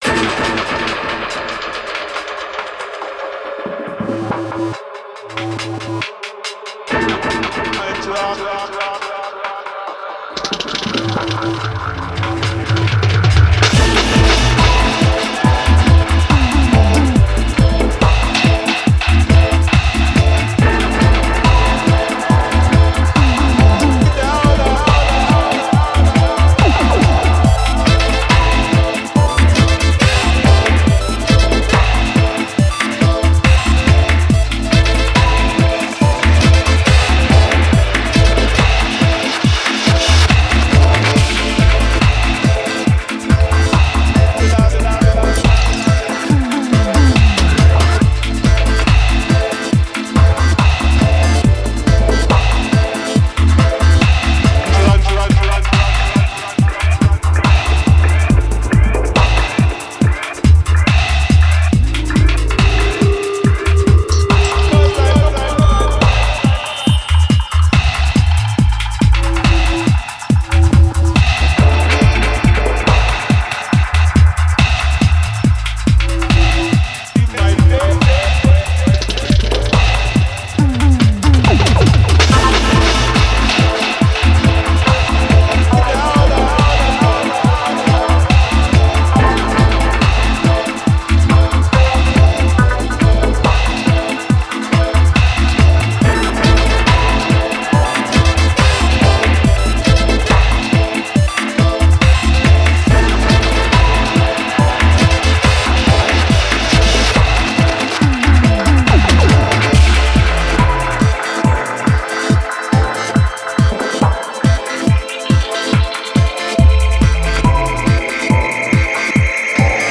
Genre:Dub
気分を高めるハイエナジーなステッパーズチューンに備えましょう。
サブベースドロップを含む2つのエレキベースラインをフィーチャーし、低音域に迫力を与えます。
また、ピアノ、ギター、シンセのスカンクやリフも豊富に収録されており、本格的なダブの雰囲気を加えるのに最適です。
さらに、3パートのサックスラインがミックスの中で旋律を奏で、FXやボーカルエコーが彩りを加えます。
デモサウンドはコチラ↓